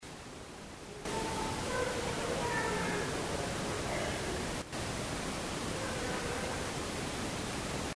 The following clips were obtained from Burn Brae Mansion.
This is the room with the alleged secret child’s room.
There is no consensus as to what is being said. There is what sounds like a cough after the first part and then the second part. Both sound like they are the same voice.